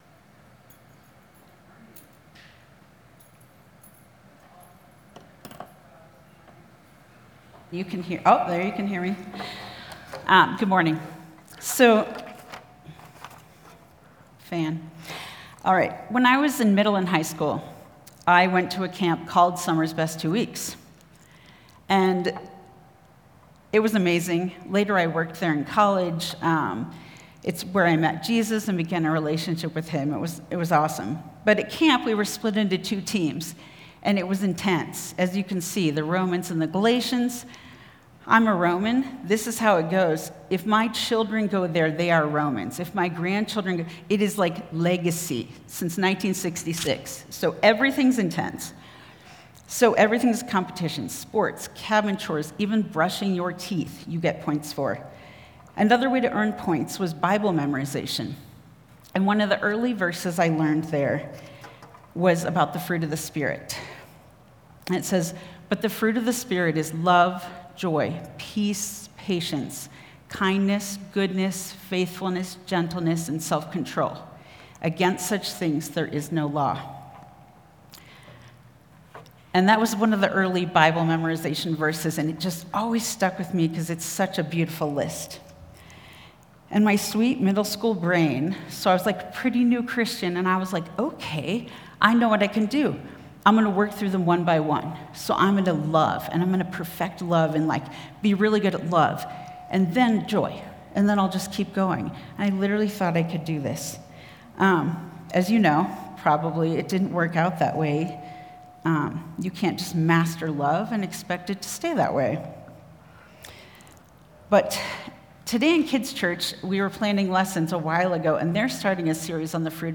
This sermon explores the first Fruit of the Spirit—love—and what it looks like to live out agape love in everyday life.